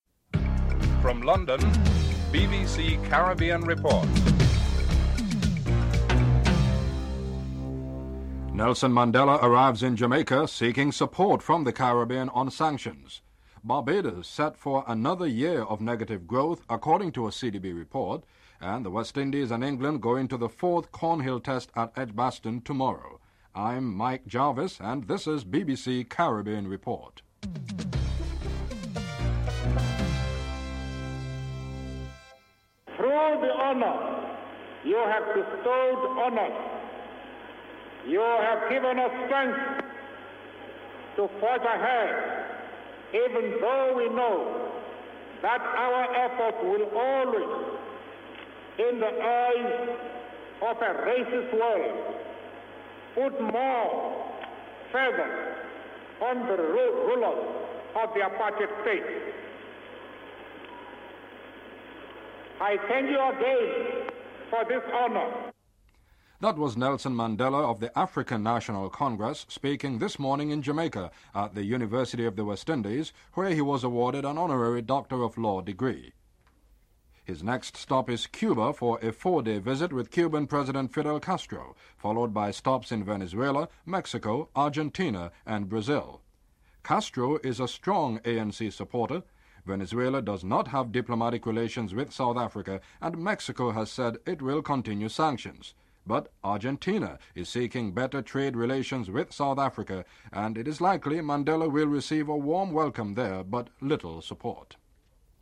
2. Nelson Mandela arrives in Jamaica today seeking support from the Caribbean on sanctions. The report features an excerpt of a speech by Nelson Mandela at the University of the West Indies where he was awarded an honorary doctor of law degree (00:32-01:45)
7. West Indies and England start the fourth Cornhill test tomorrow plagued by injuries and illnesses. Comments from captains Graham Gooch and Vivian Richards (12:03-14:50)